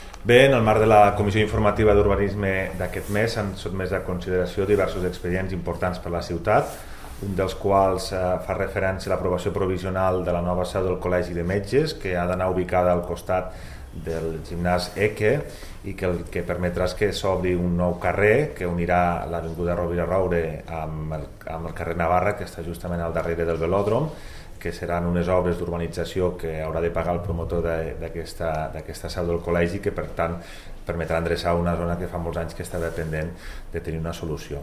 Tall de veu de Toni Postius sobre el pla de millora per la nova seu del Col·legi de Metges (849.3 KB) Tall de veu de Toni Postius sobre l'actuació al carrer Baldomer Gili i els nous límits del terme municipal (2.8 MB)
tall-de-veu-de-toni-postius-sobre-el-pla-de-millora-per-la-nova-seu-del-col-legi-de-metges